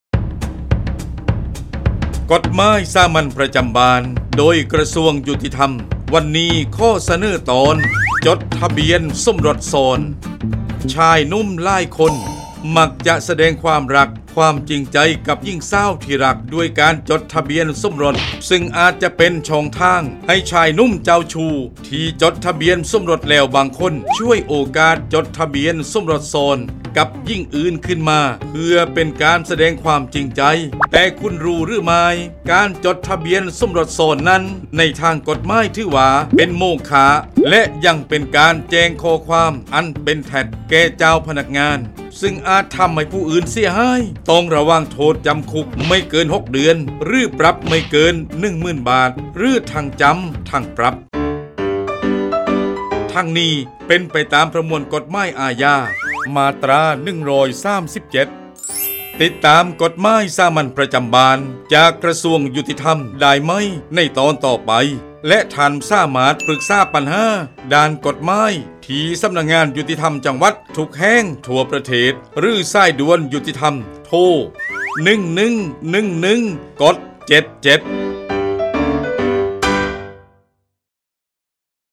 กฎหมายสามัญประจำบ้าน ฉบับภาษาท้องถิ่น ภาคใต้ ตอนจดทะเบียนสมรสซ้อน
ลักษณะของสื่อ :   บรรยาย, คลิปเสียง